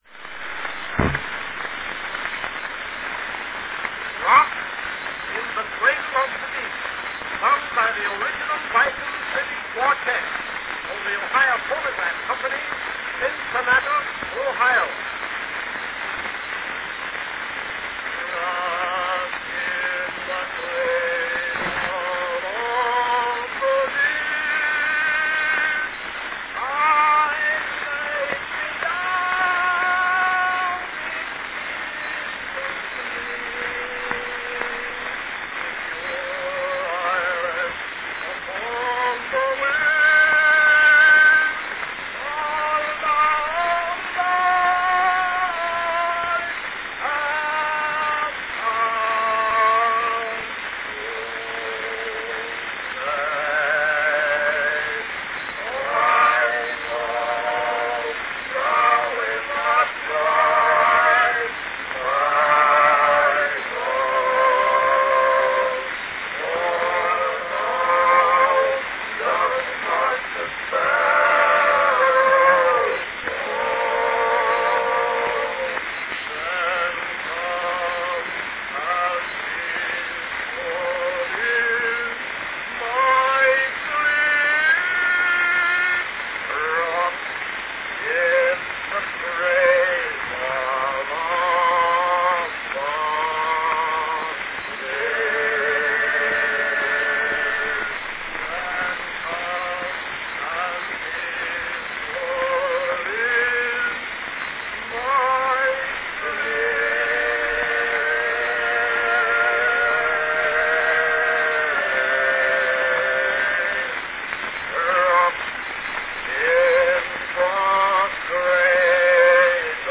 Cylinder of the Month
A scarce recording by the Original Bison City Quartette: from 1892, Rocked in the Cradle of the Deep.
Category Male quartette
Performed by Original Bison City Quartette